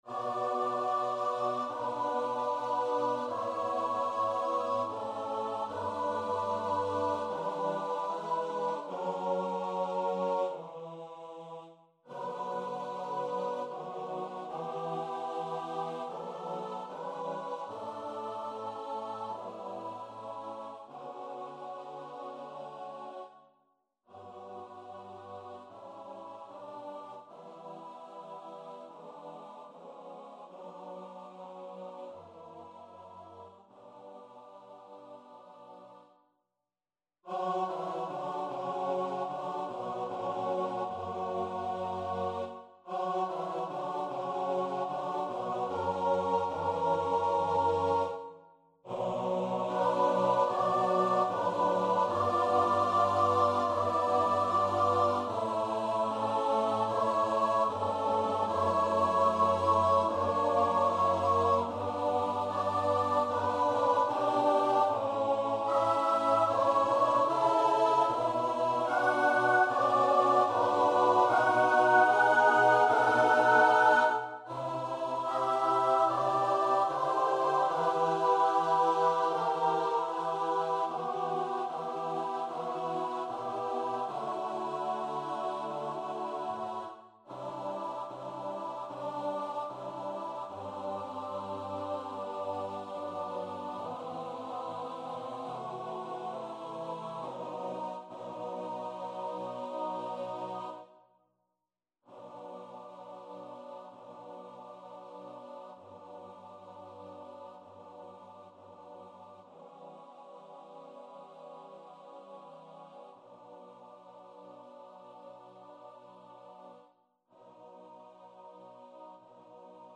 ADORAMUS TE CHRISTE SATB.mp3